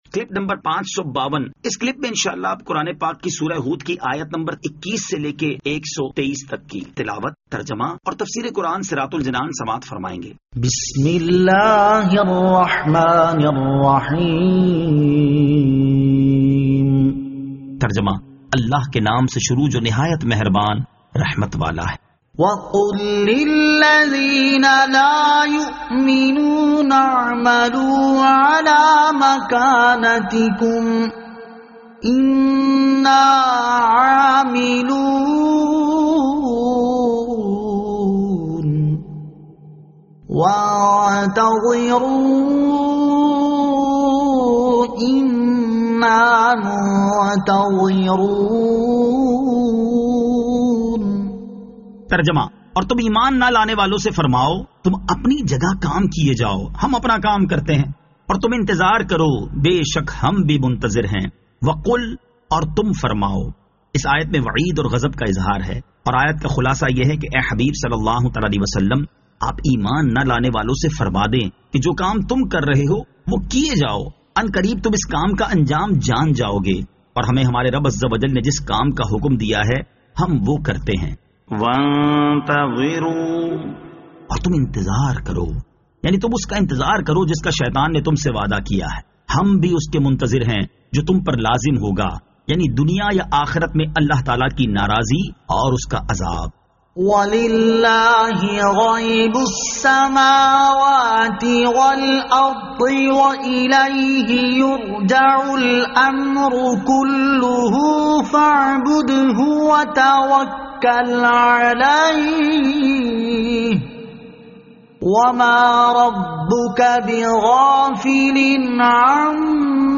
Surah Hud Ayat 121 To 123 Tilawat , Tarjama , Tafseer